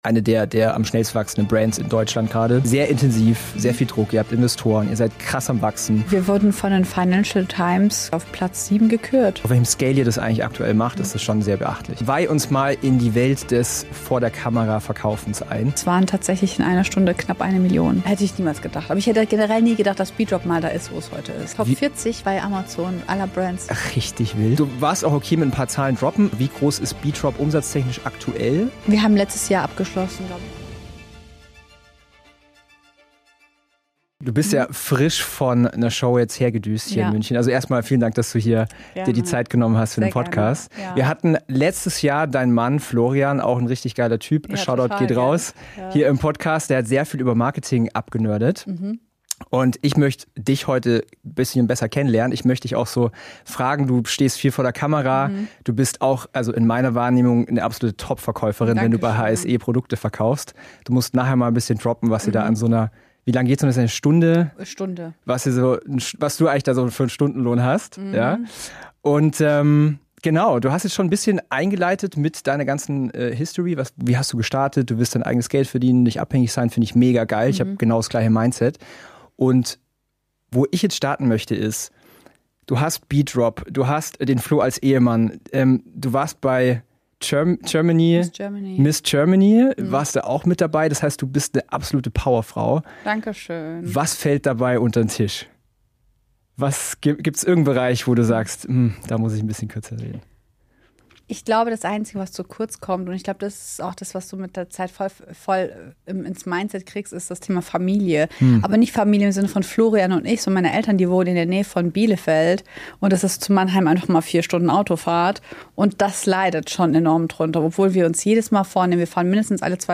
Wie bedrop 42 Mio. Umsatz pro Jahr macht | Interview